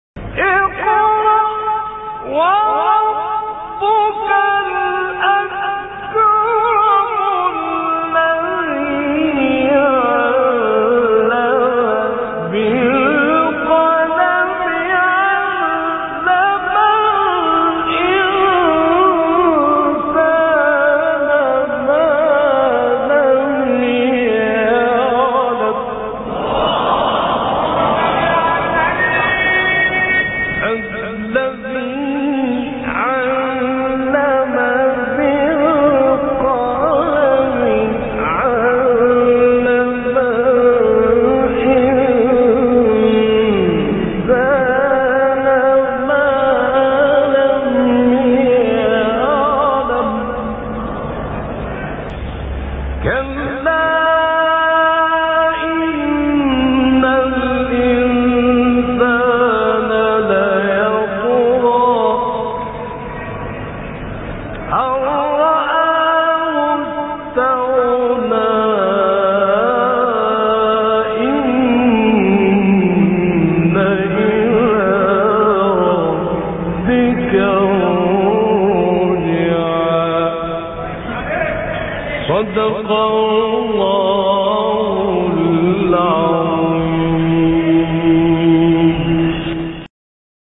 برچسب ها: خبرگزاری قرآن ، ایکنا ، فعالیتهای قرآنی ، مقاطع صوتی ، فراز صوتی ، تلاوت ، راغب مصطفی غلوش ، شحات محمد انور ، محمد الفیومی ، محمود شحات انور ، محمد عبدالعزیز حصان ، متولی عبدالعال ، مقطع میلیونی مصطفی اسماعیل ، قرآن